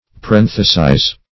Search Result for " parenthesize" : The Collaborative International Dictionary of English v.0.48: Parenthesize \Pa*ren"the*size\ (p[.a]*r[e^]n"th[-e]*s[imac]z), v. t. To make a parenthesis of; to include within parenthetical marks.